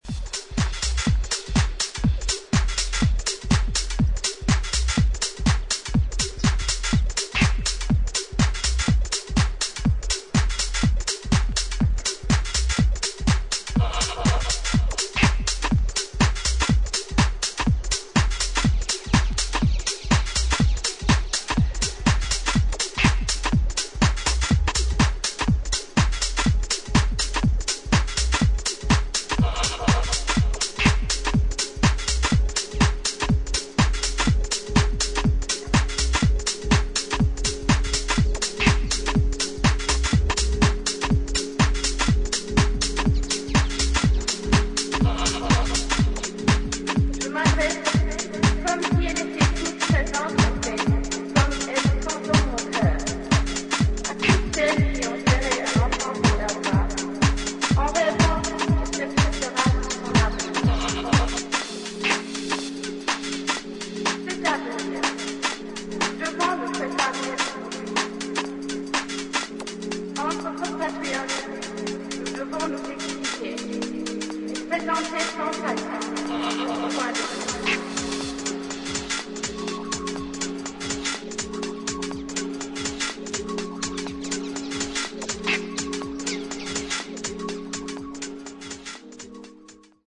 』で構成された、クールなテックハウス4曲を収録。